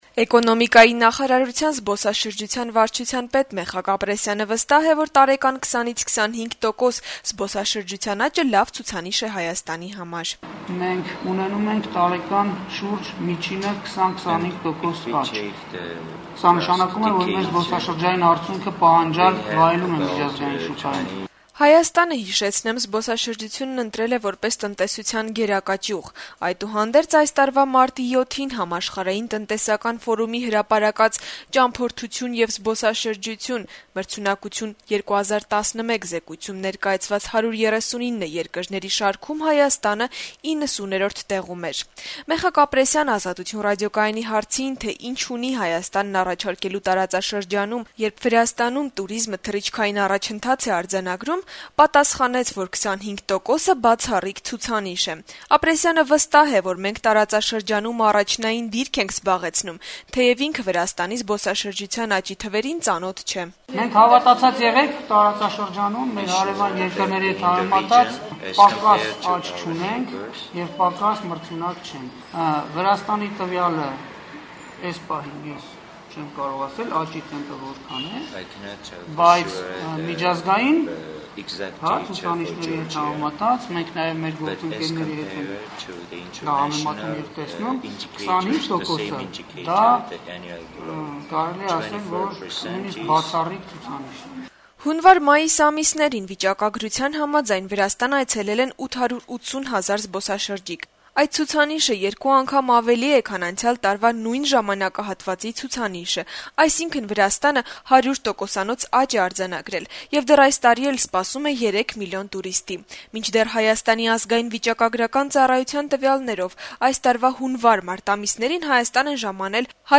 «Սա նշանակում է, որ մեր զբոսաշրջային արդյունքը պահանջարկ է վայելում միջազգային շուկայում», - երեքշաբթի օրը կայացած ասուլիսում ասաց Ապրեսյանը: